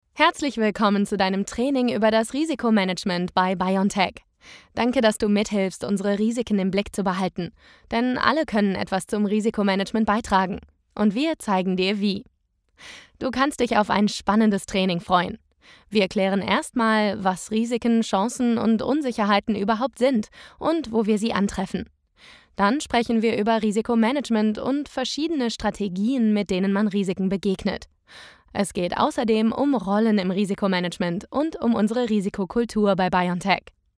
Natürlich, Cool, Verspielt, Vielseitig, Freundlich
Erklärvideo